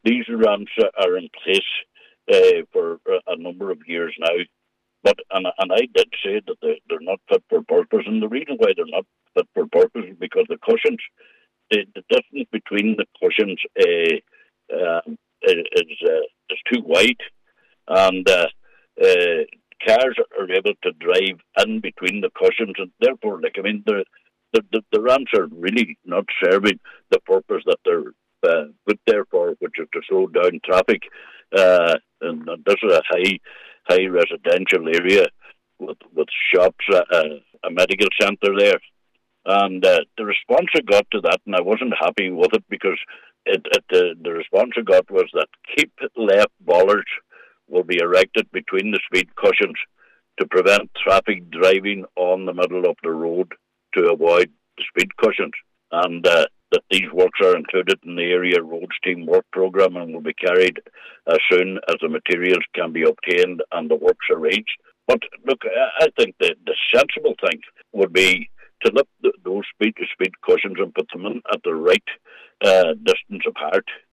However, Cllr Coyle says he wasn’t satisfied with this: